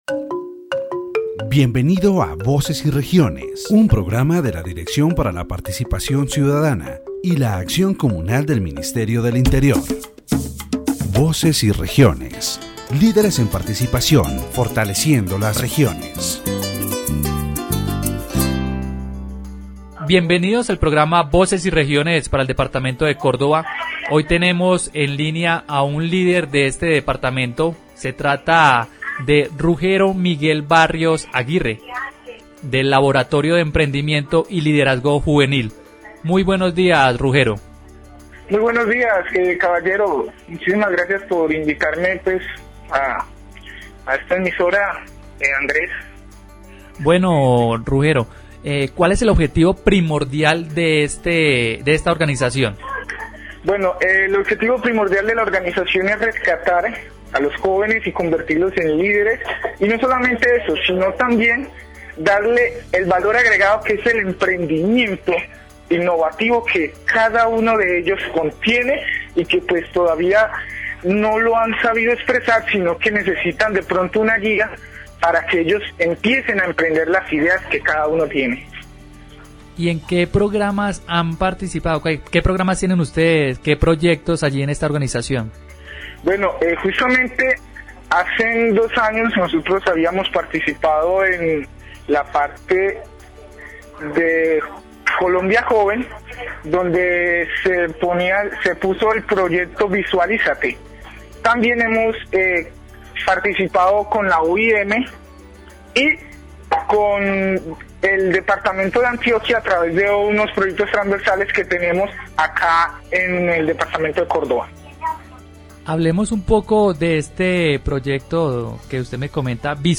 In the Voces y Regiones program, The interviewee discusses the importance of rescuing and strengthening young leaders in the region, particularly in the Córdoba area of Colombia.